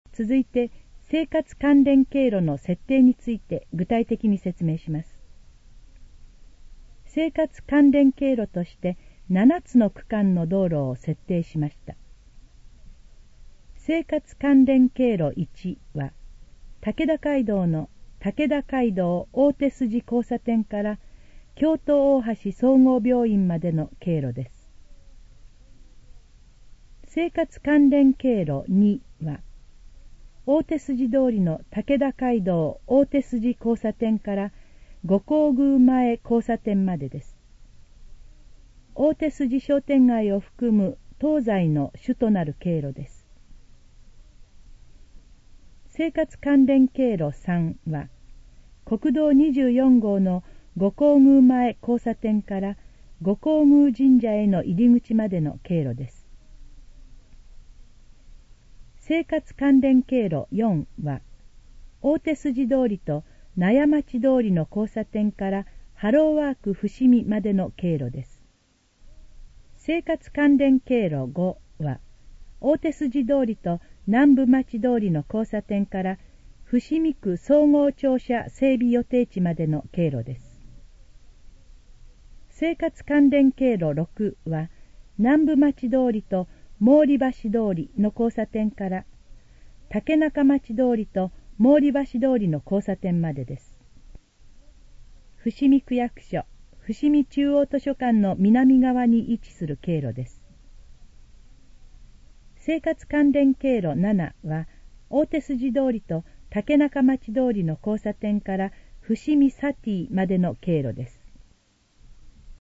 以下の項目の要約を音声で読み上げます。
ナレーション再生 約440KB